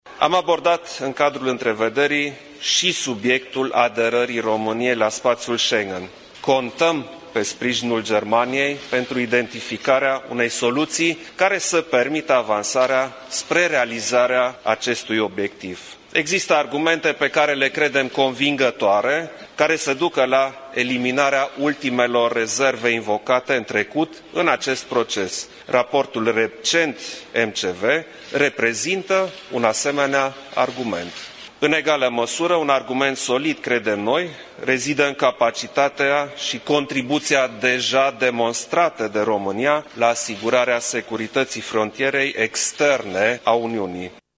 Declarația a fost făcută de președintele Klaus Iohannis în urma discuțiilor avute cu cancelarul german Angela Merkel.